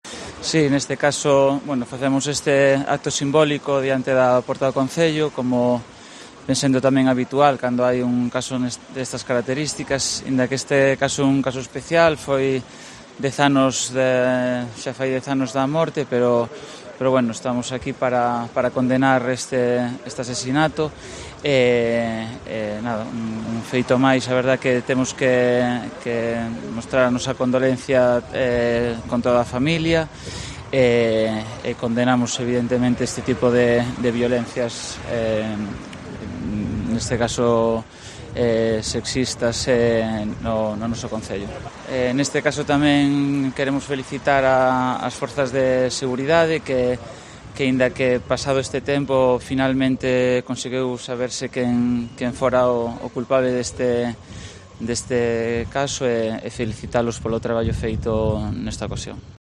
Román Romero, portavoz del gobierno municipal de Narón